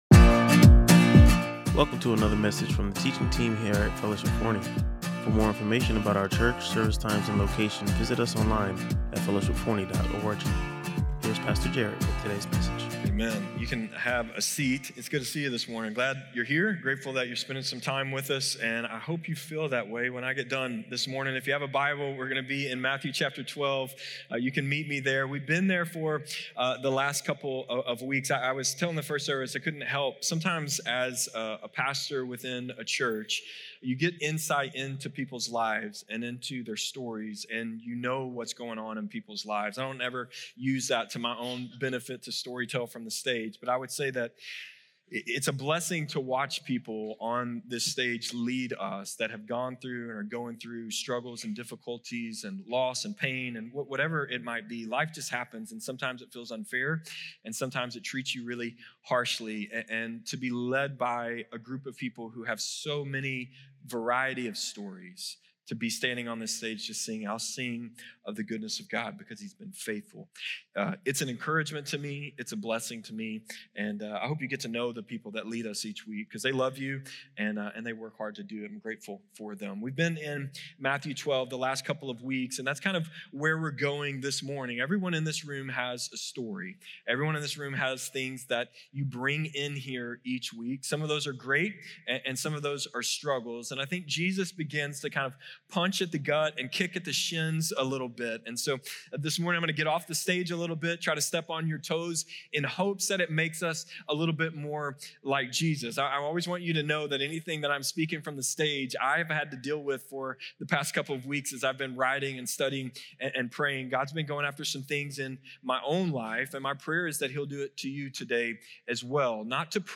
The sermon emphasized that morality and salvation are not the same thing, and that no amount of good works can earn God’s favor. Rather, it’s through grace and authentic relationship with Jesus that true transformation occurs.